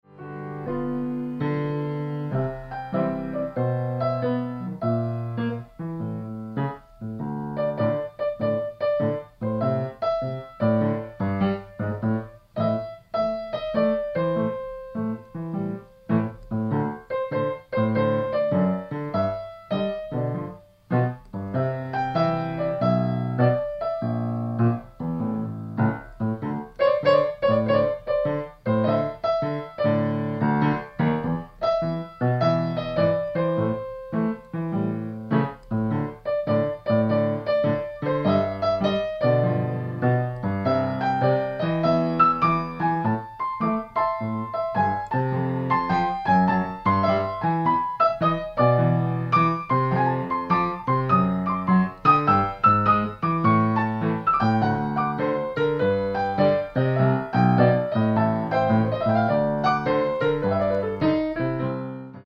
Style: Vaudeville Blues Piano